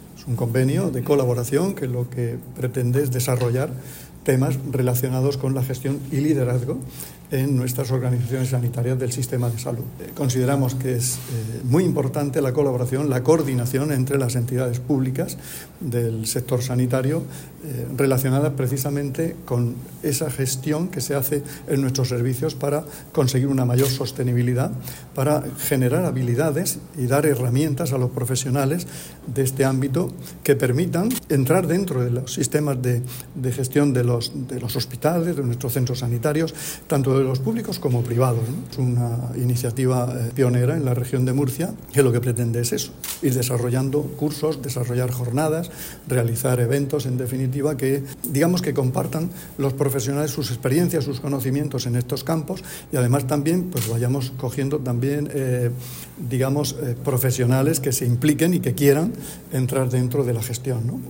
Sonido/ Declaraciones del consejero de Salud,  Juan José Pedreño, sobre el convenio para realizar actividades docentes en el ámbito de la salud.